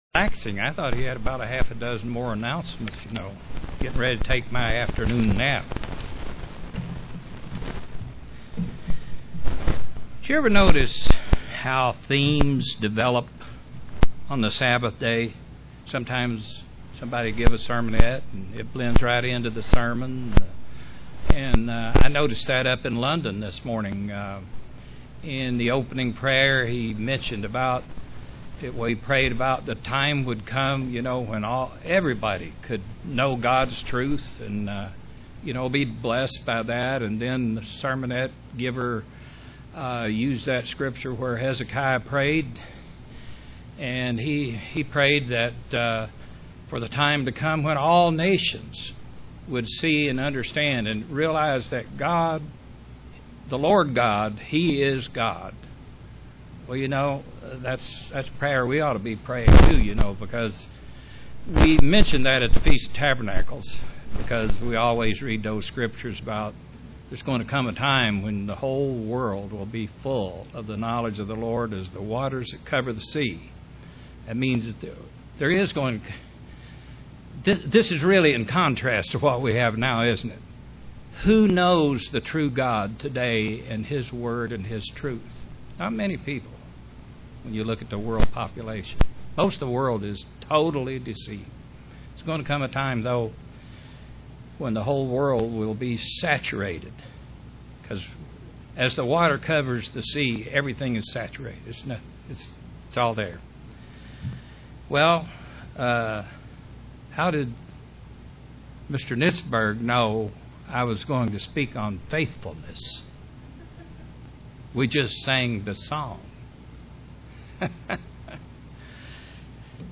Print God is ever faithful unlike the spot faithfulness of man UCG Sermon Studying the bible?